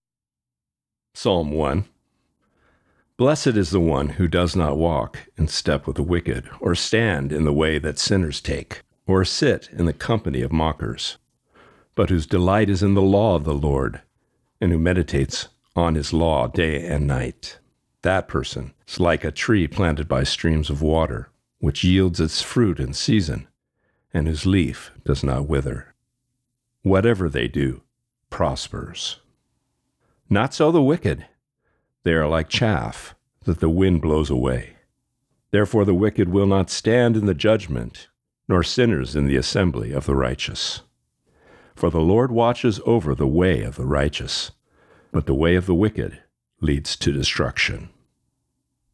Reading: Psalm 1 (NIV)*